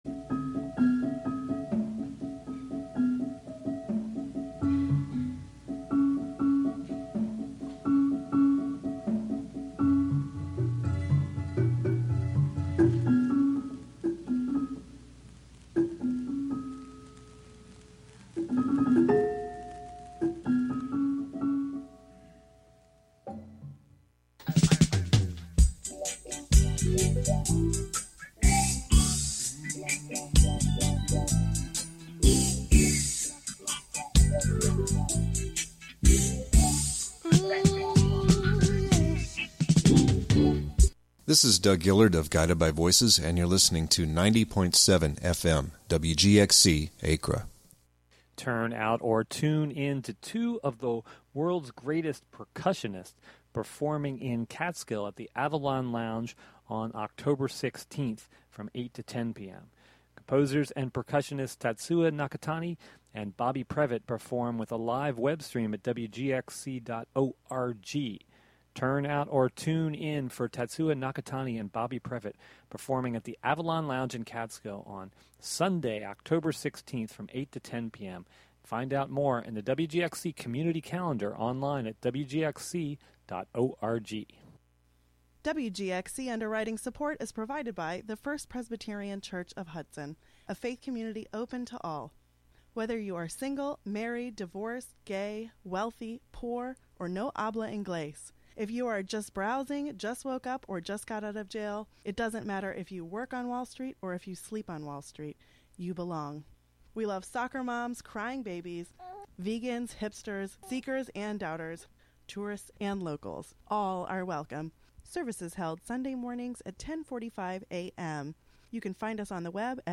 Cello Show: Oct 16, 2022: 7pm - 8pm